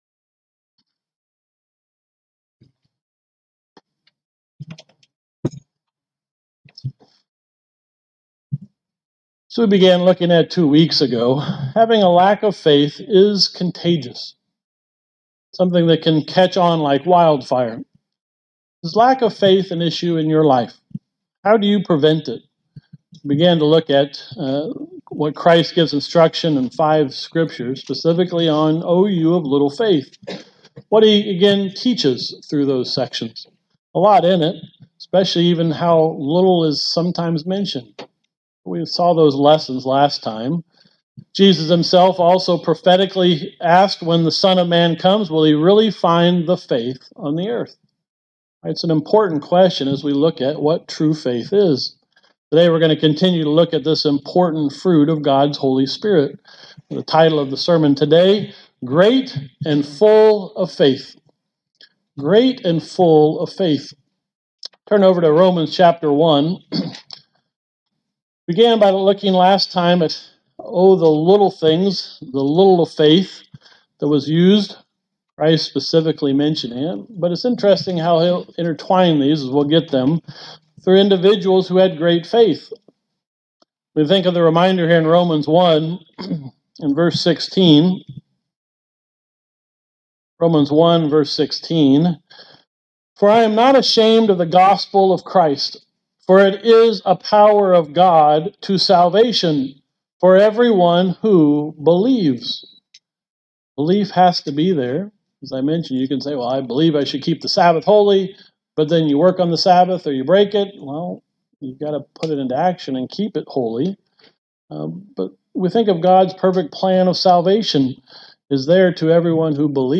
This sermon looks at examples of individuals who had great faith and were full of faith and what we can learn from them.